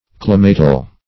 climatal - definition of climatal - synonyms, pronunciation, spelling from Free Dictionary Search Result for " climatal" : The Collaborative International Dictionary of English v.0.48: Climatal \Cli"ma*tal\, a. Climatic.